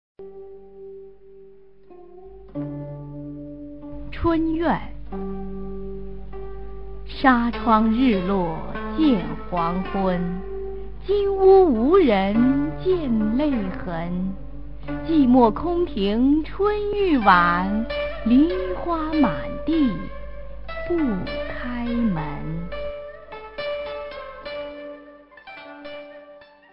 [隋唐诗词诵读]金昌绪-春怨 唐诗吟诵